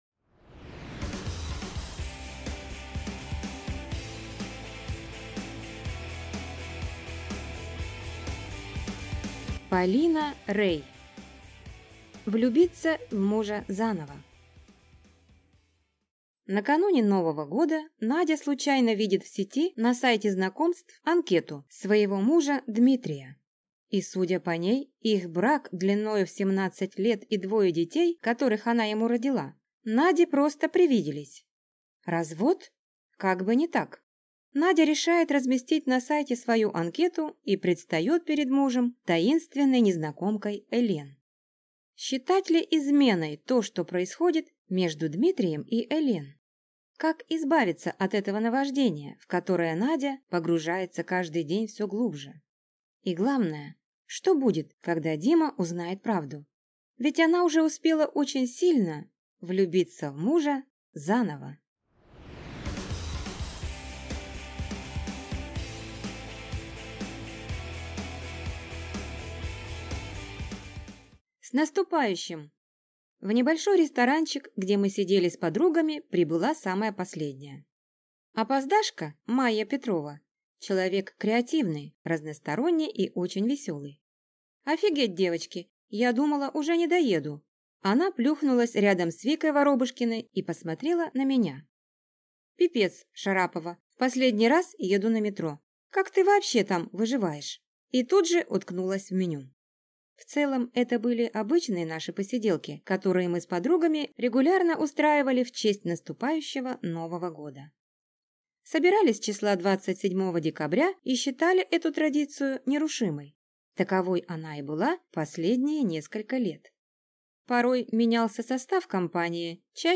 Аудиокнига Влюбиться в мужа заново | Библиотека аудиокниг